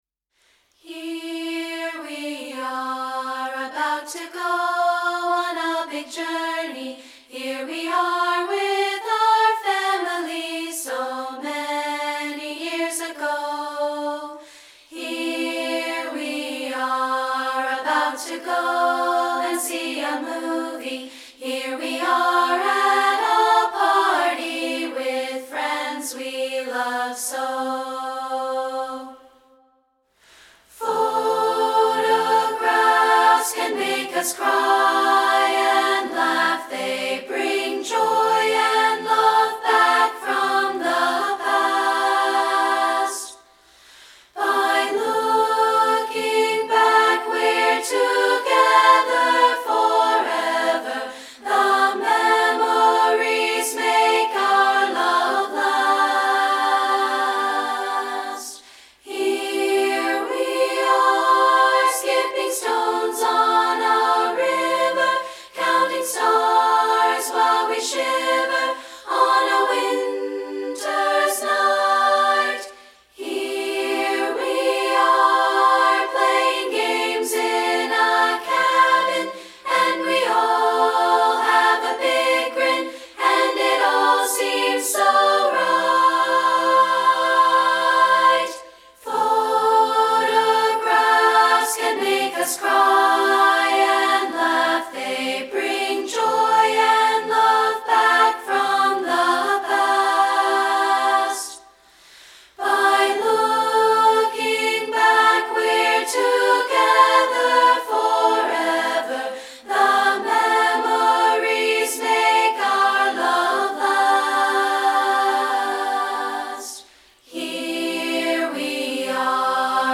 including this a cappella version.